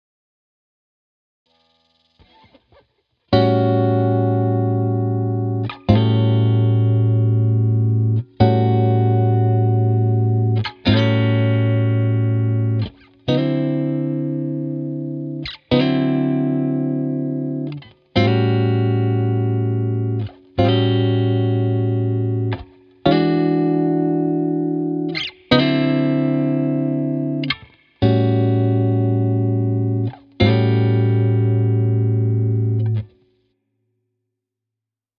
In the next 12 bar we’ll play the same chords as in the last example, but this time we’ll substitute the A7 chord by the jazzy sounding A13 chord.
This A13 chord is starting to send us in the direction of a jazz blues song.